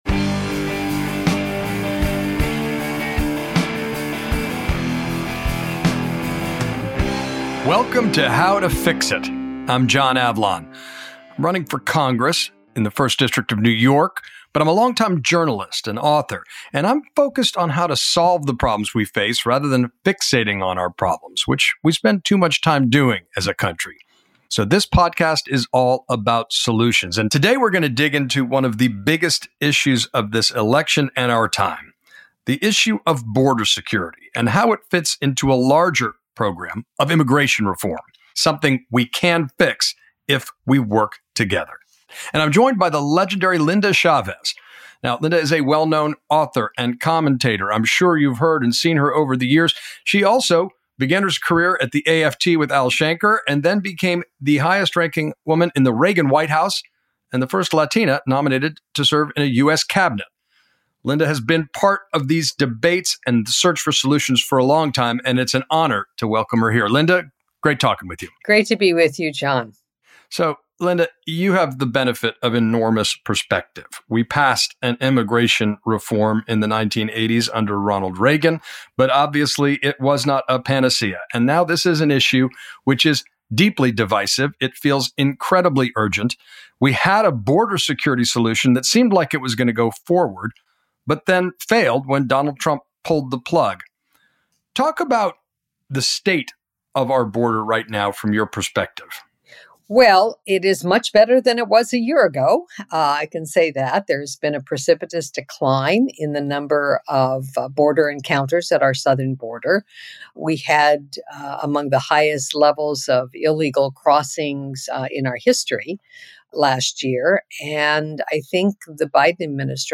But a number of fixes could help, including changing asylum rules—a key part of the border bill that Trump had killed. Linda Chavez joins John Avlon to break it all down.